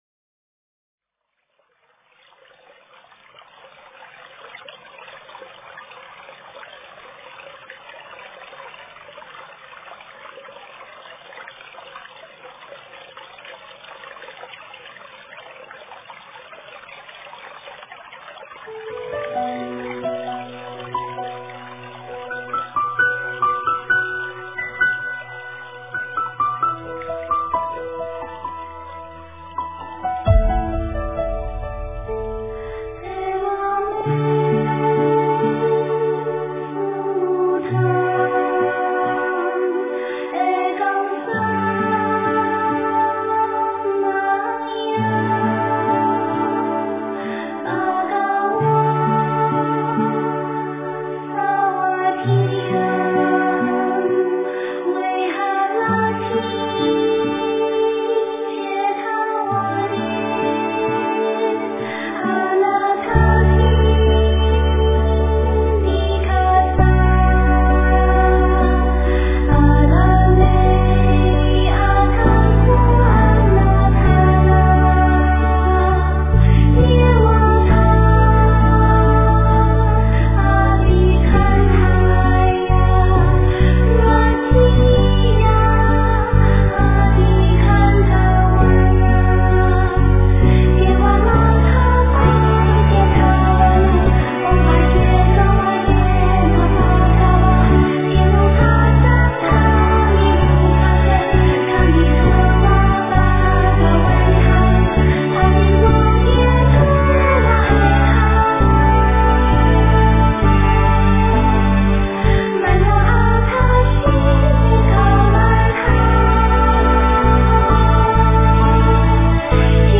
诵经
佛音 诵经 佛教音乐 返回列表 上一篇： 观音灵感普门品 下一篇： 药师经 相关文章 地藏经(男声念诵）5 地藏经(男声念诵）5--未知...